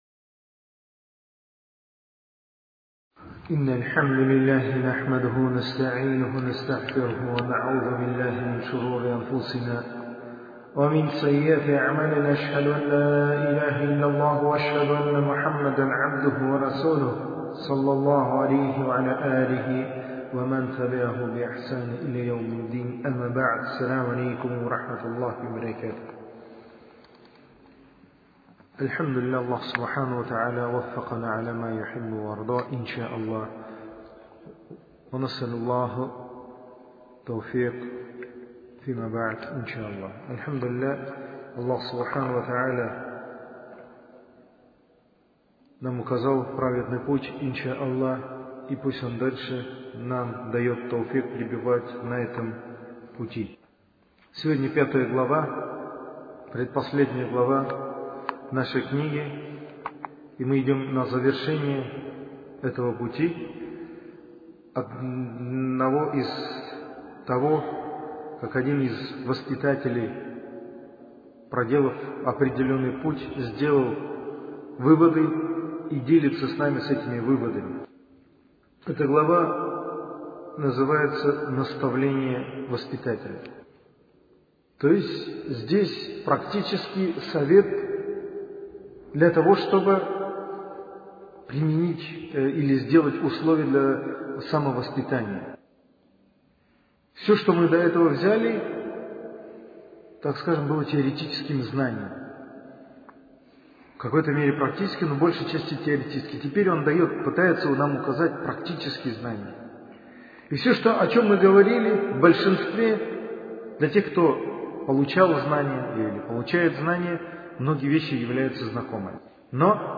Цикл лекций